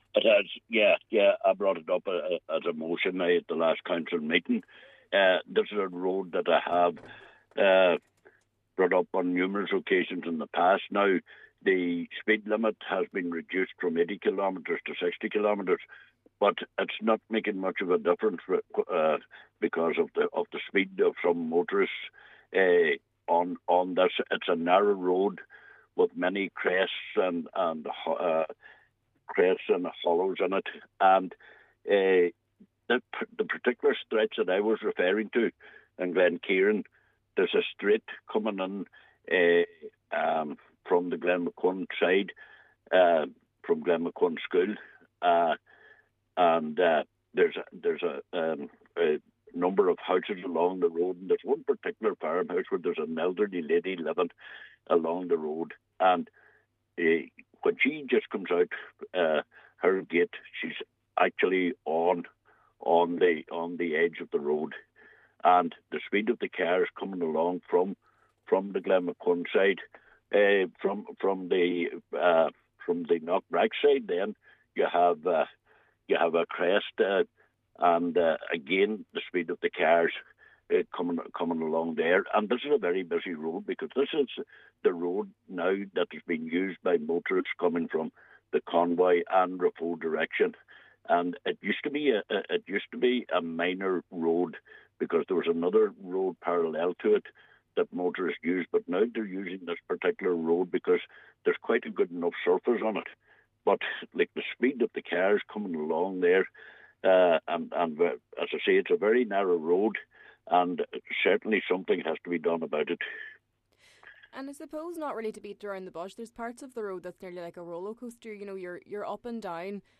Cllr Coyle says there are people in the area living in fear, and something must be done: